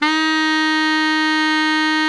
BARI  FF D#3.wav